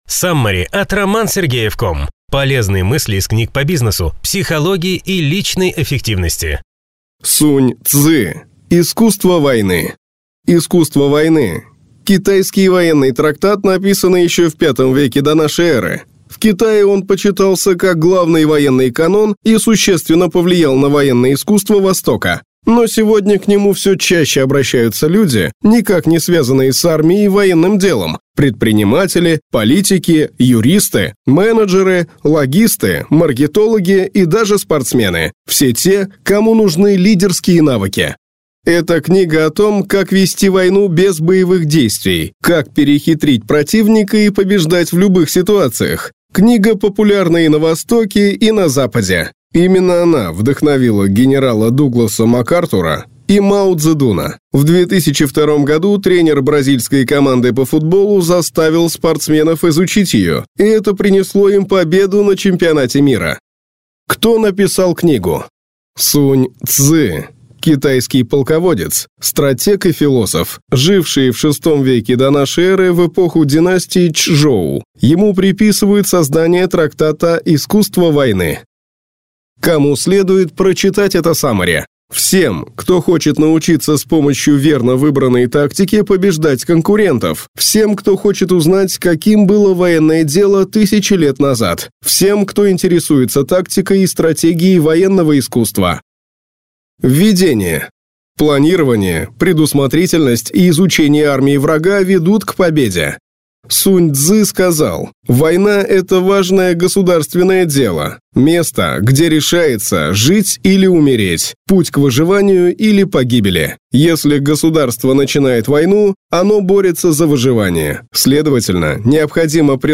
Аудиокнига Саммари на книгу «Искусство войны». Сунь-Цзы | Библиотека аудиокниг